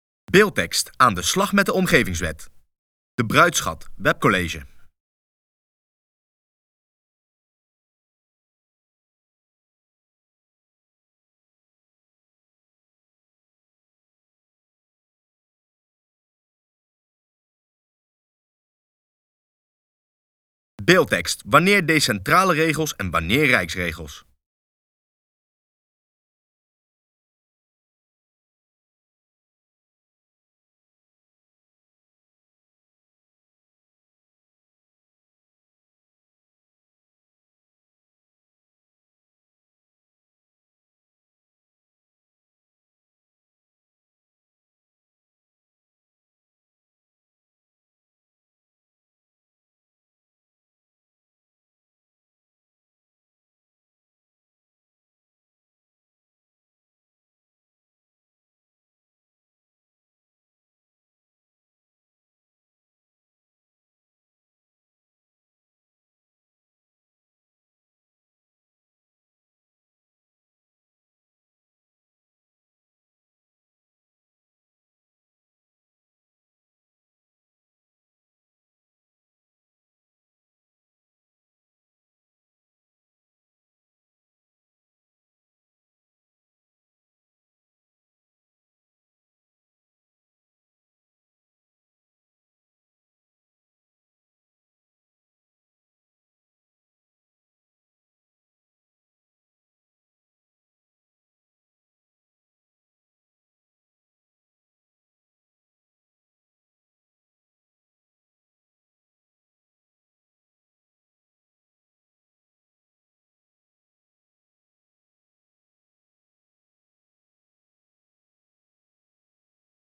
Webcollege Bruidsschat